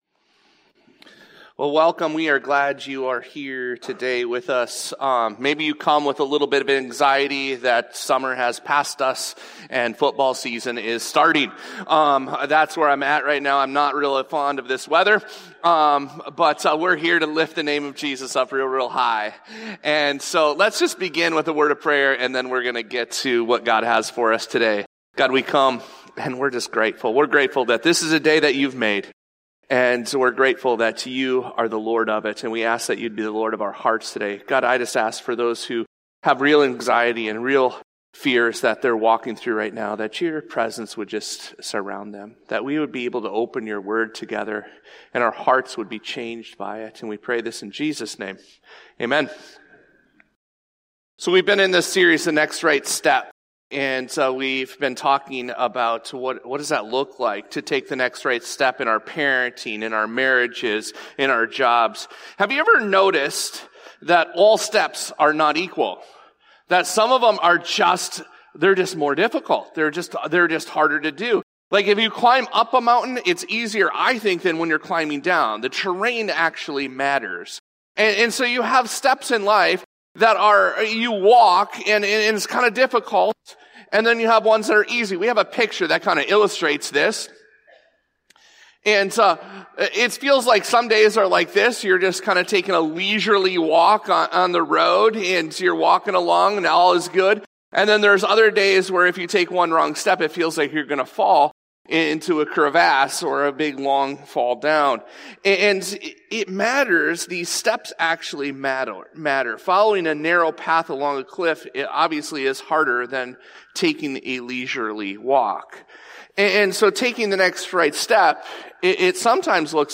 This podcast episode is a Sunday message from Evangel Community Church, Houghton, Michigan, May 18, 2025.